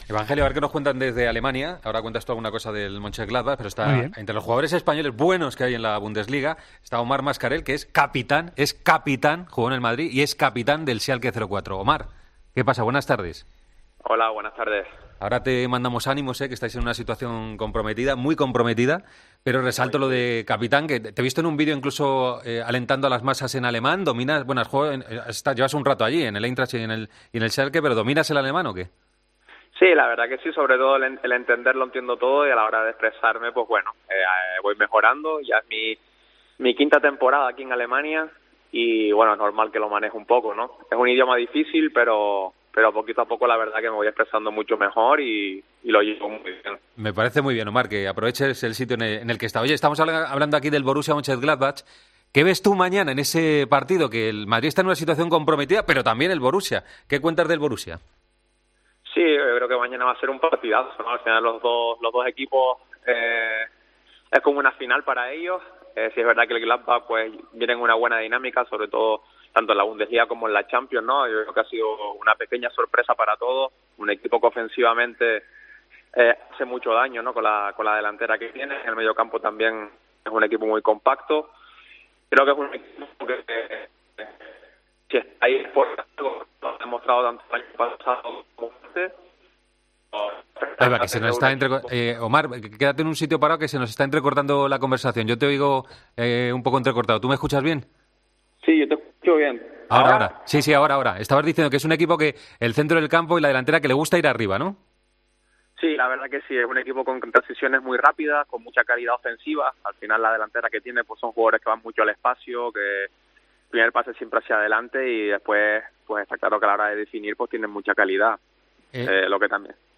El capitán del Schalke 04 atiende a Deportes COPE y habla sobre el próximo rival del Real Madrid: "El Gladbach viene en buena dinámica, ha sido una sorpresa para todos"